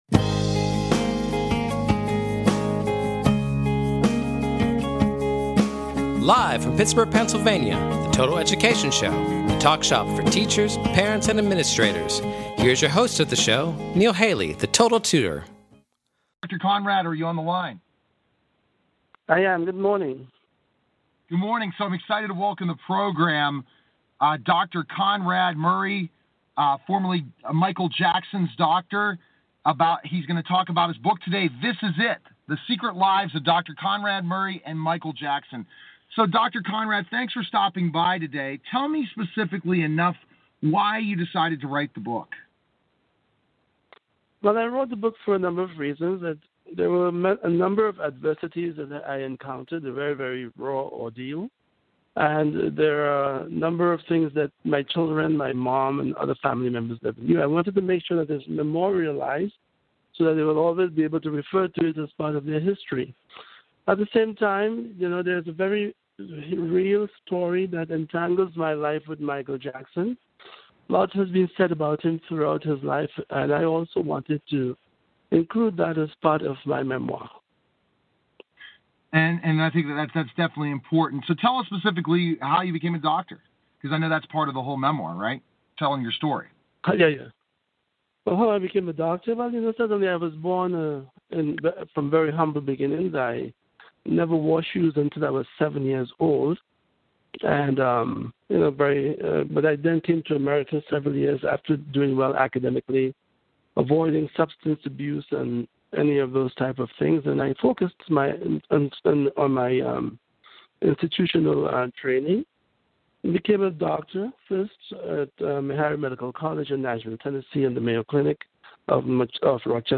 Total Education Celebrity Show {also known as "The Total Education Hour" is an educational talk show that focuses on the listeners' needs. Catch weekly discussions focusing on current education news at a local and national scale.